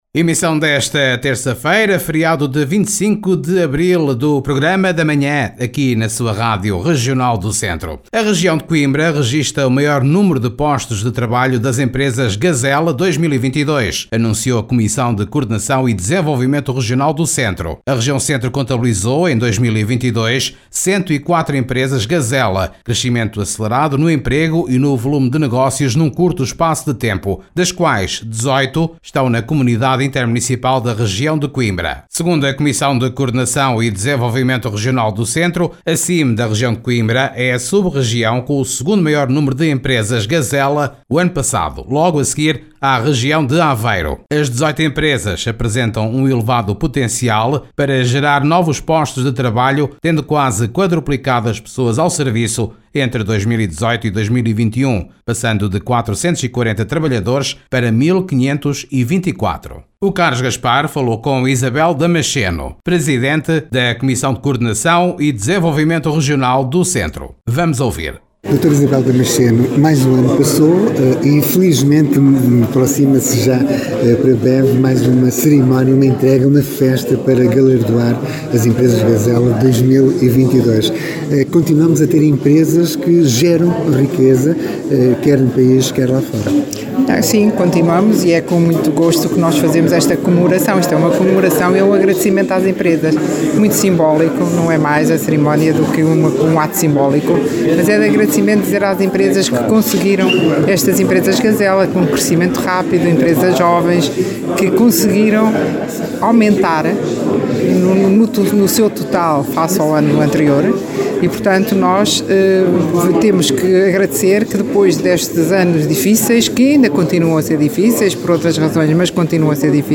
falou com a Presidente da CCDRC, Isabel Damasceno.